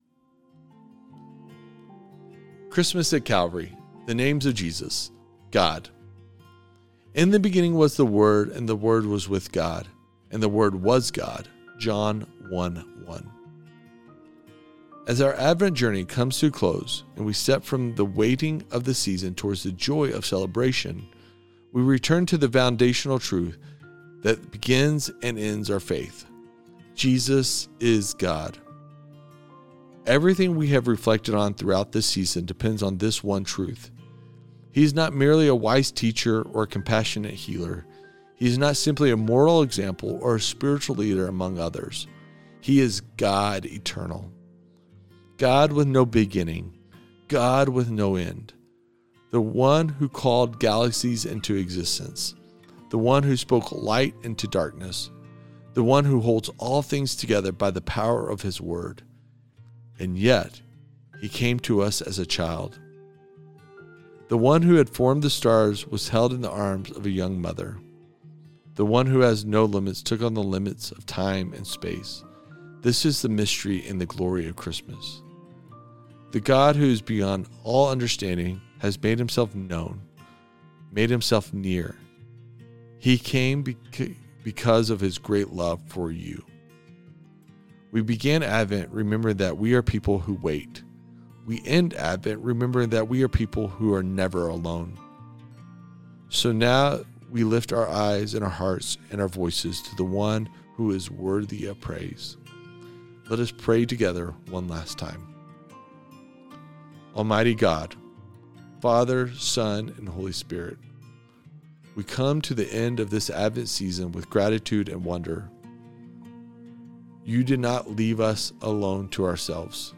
Advent Readings & Prayer